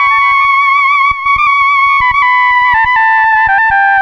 Synth 15.wav